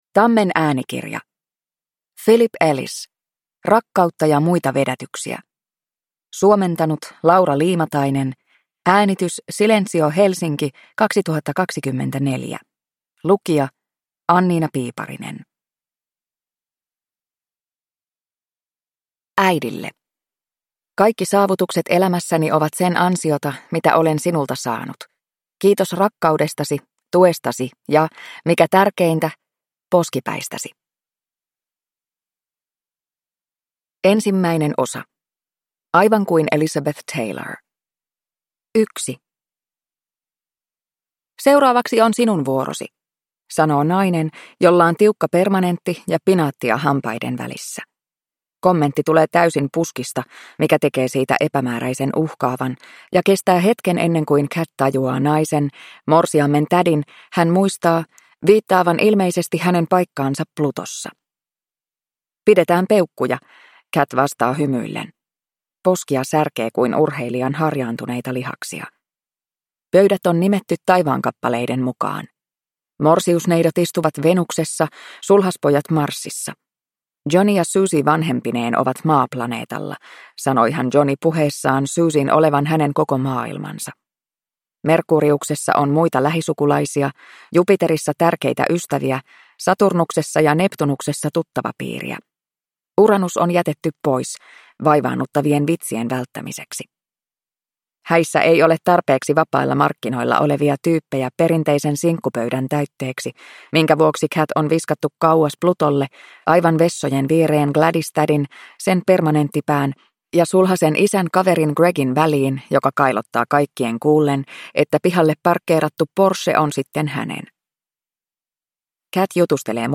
Downloadable Audiobook
Ljudbok